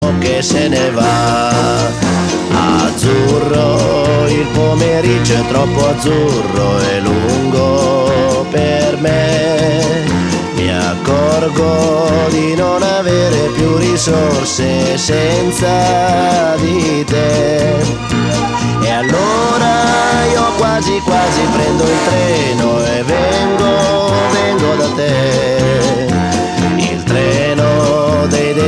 Besetzung: Blasorchester
für Blasorchester (mit Gesang).
Tonart: Es + C-Dur
Stil: Disco Beat